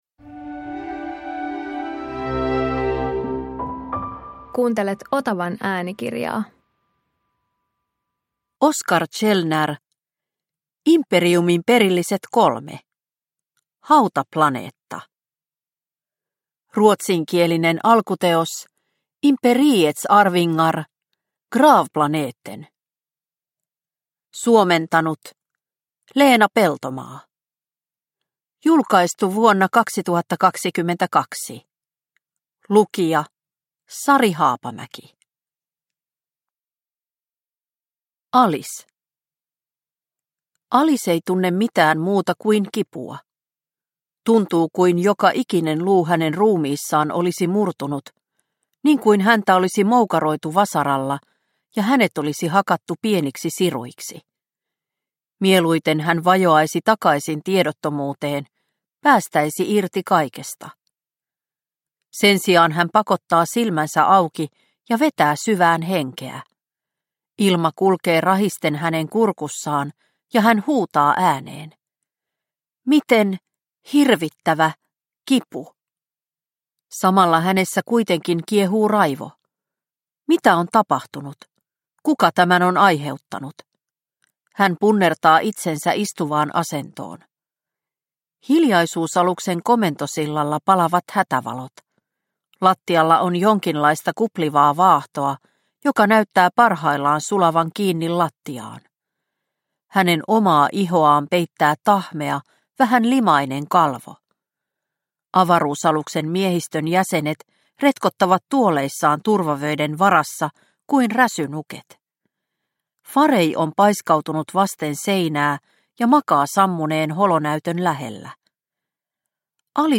Imperiumin perilliset 3 Hautaplaneetta – Ljudbok